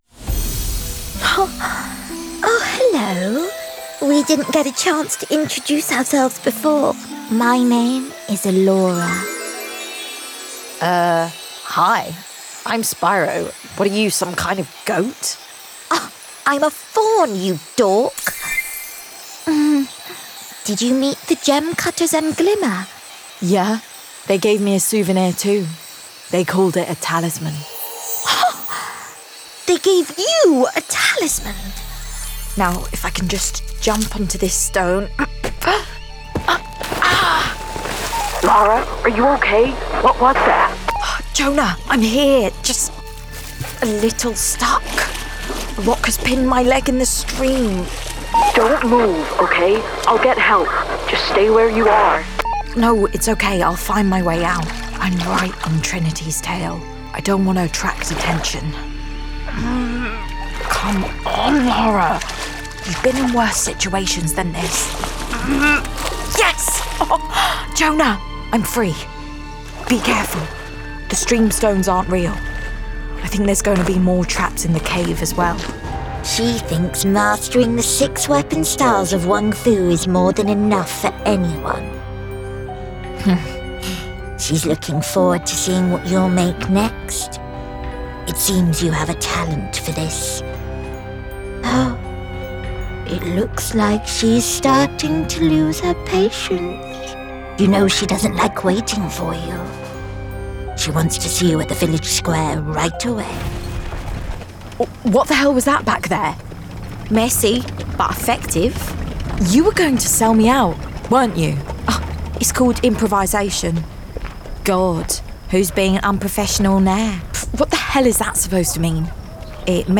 Games Reel
RP ('Received Pronunciation')
Character, Versatile, Acting, Confident, Games